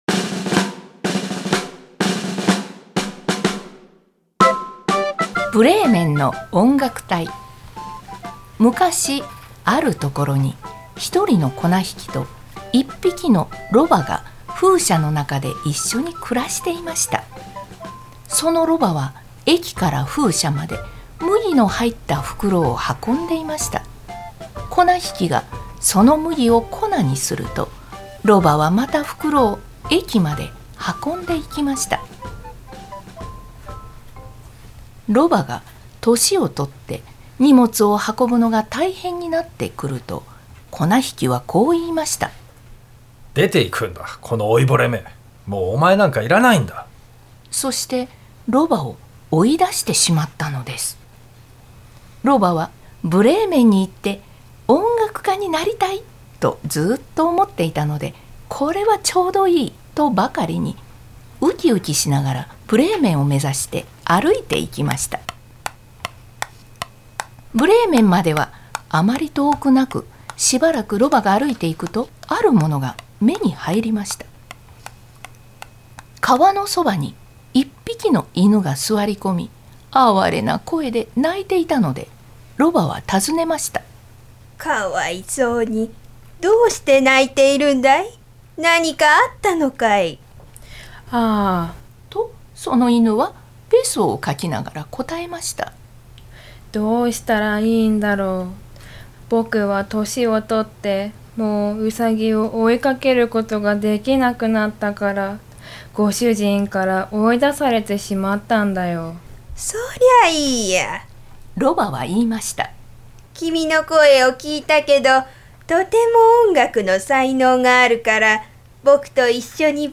「ブレーメンの音楽隊」の朗読を行いました
大学、児童養護施設の先生、大学生、市職員等が一緒に楽しくレコーディングを行い、ブレーメンにデータを送ったところです。